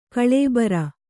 ♪ kaḷēbara